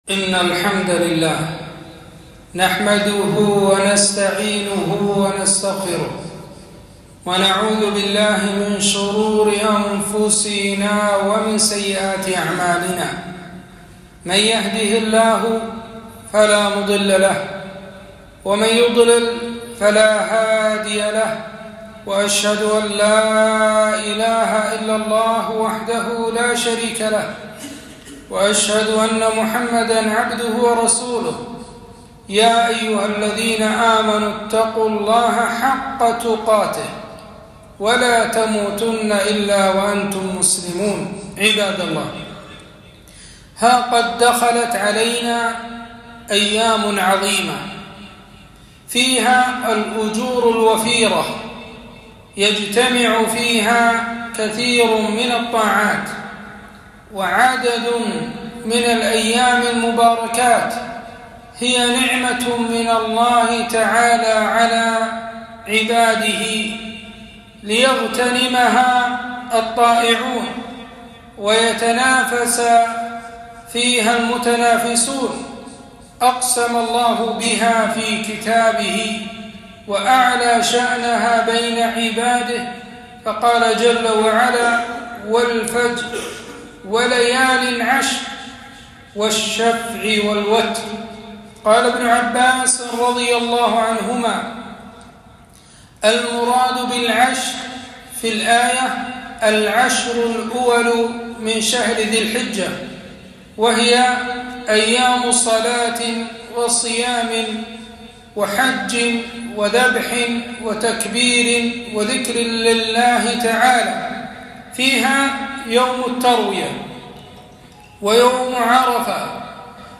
خطبة - أفضل أيام الدنيا عشر ذي الحجة